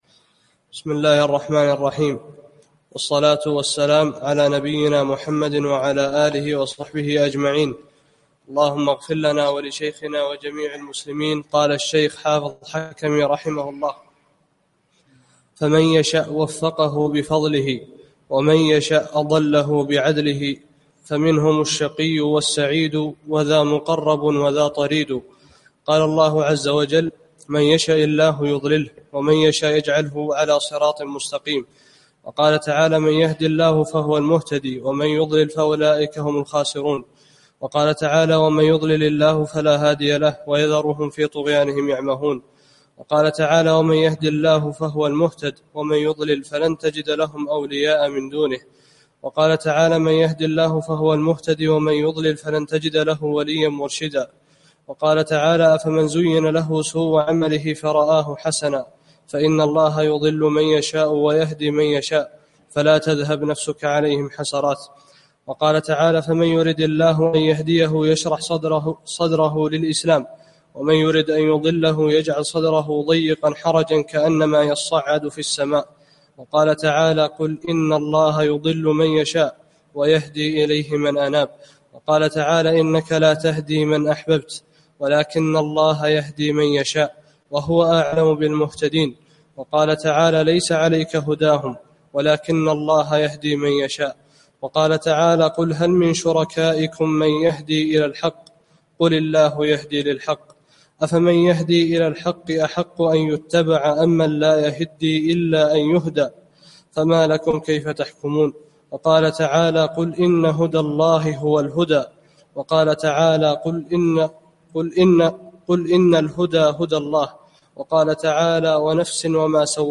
29- الدرس التاسع والعشرون